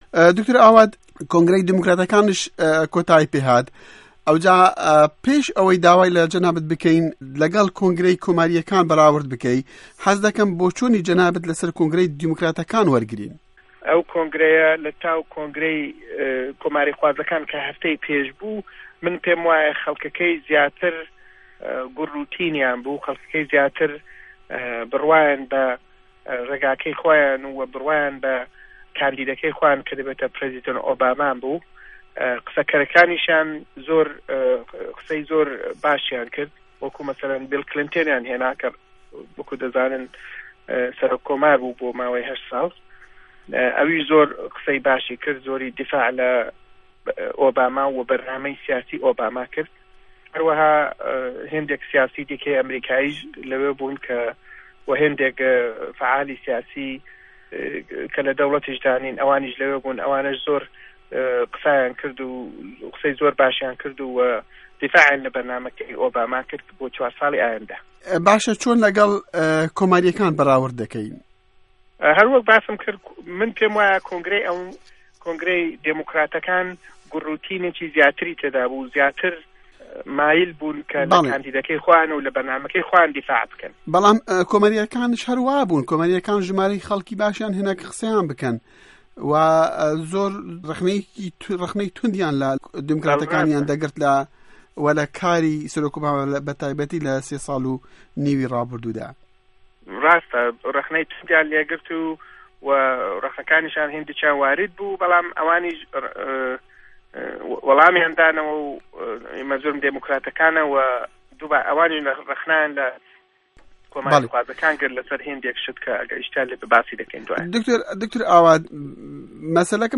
Hevpeyv'în